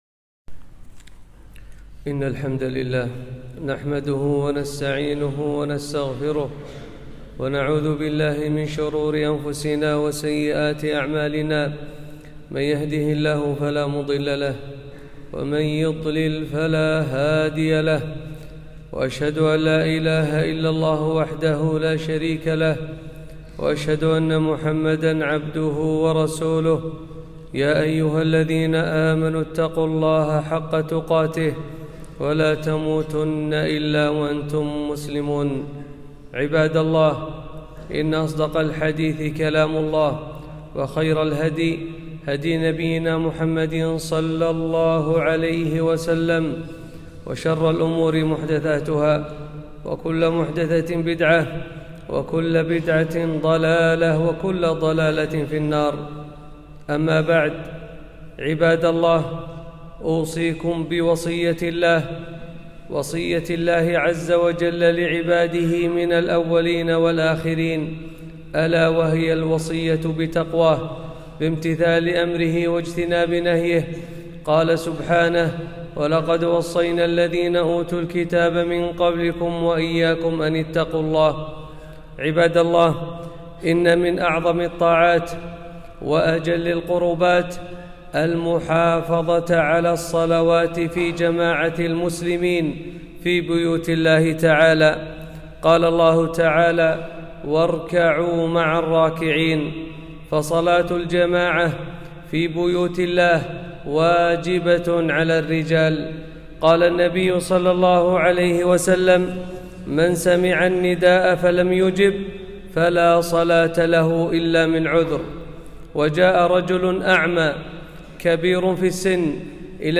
خطبة - وجوب المحافظة على الصلاة في المسجد وفضلها